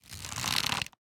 Minecraft Version Minecraft Version snapshot Latest Release | Latest Snapshot snapshot / assets / minecraft / sounds / item / crossbow / loading_middle1.ogg Compare With Compare With Latest Release | Latest Snapshot